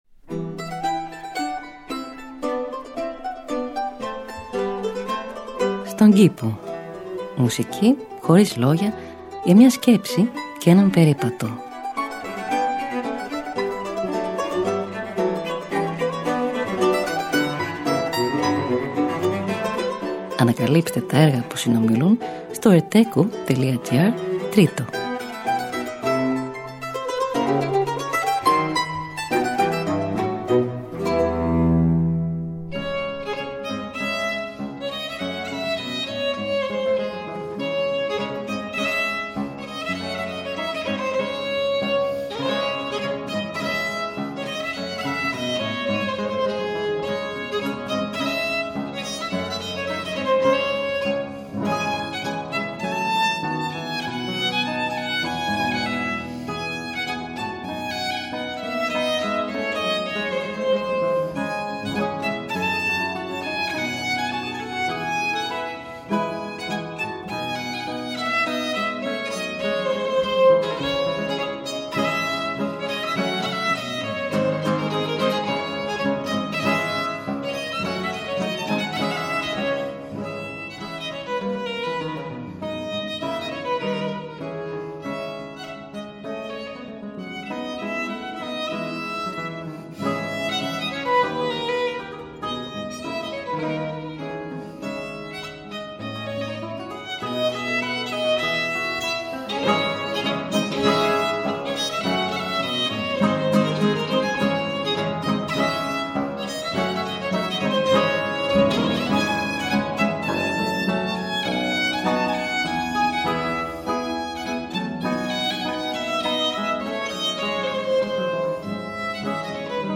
Μουσική Χωρίς Λόγια για μια Σκέψη και έναν Περίπατο.
Arrange for mandolin and continuo